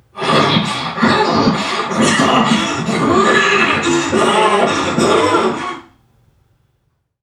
NPC_Creatures_Vocalisations_Robothead [70].wav